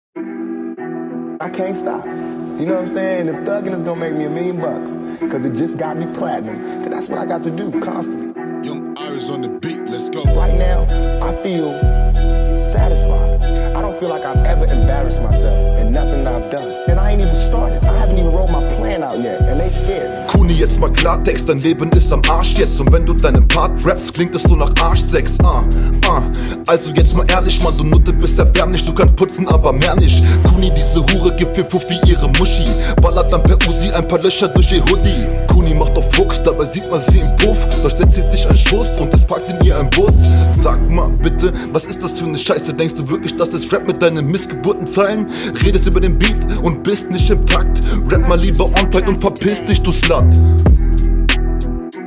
Die Runde fand ich kurz und dein Einsatz war sehr spät.